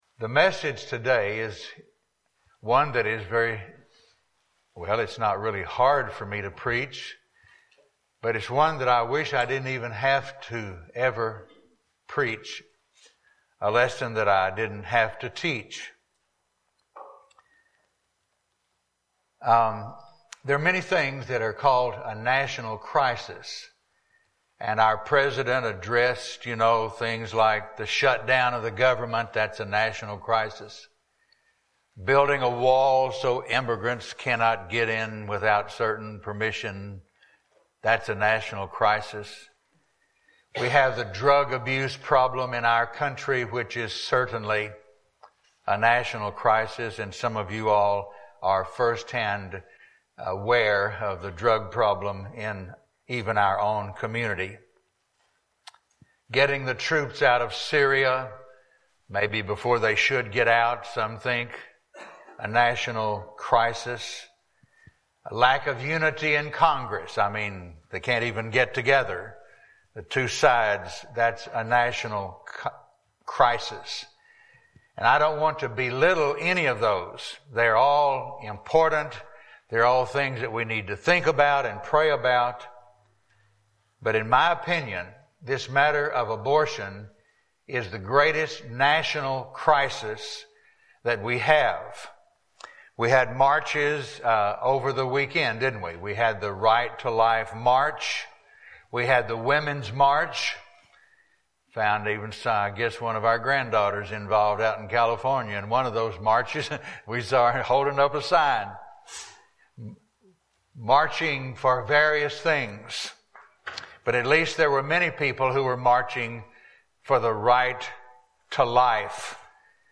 Psalm 139:13-16 Service Type: Sunday Morning Bible Text